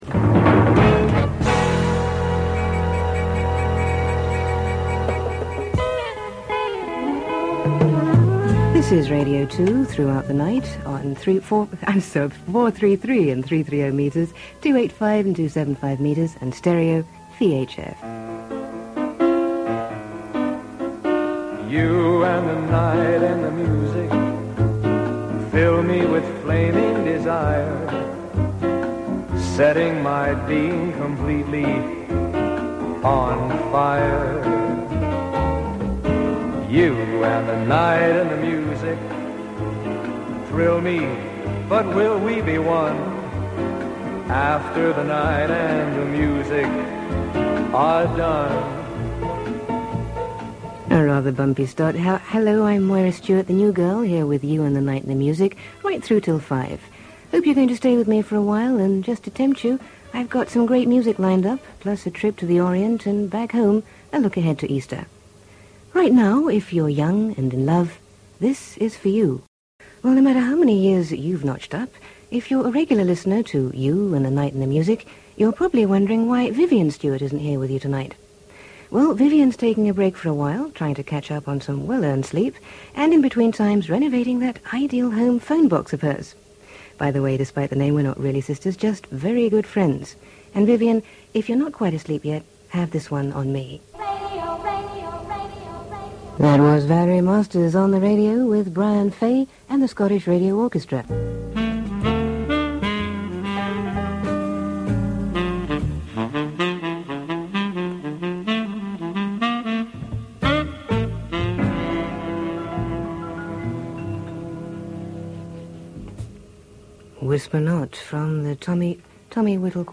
Those with long memories will remember Moira as a Radio 2 staff announcer and newsreader in the early 1980s. One of her duties was to host the overnight 2-5 a.m. show You and the Night and the Music, a show which, due to needletime restrictions,was light on records but heavy on recorded sessions by the BBC orchestras and other groups.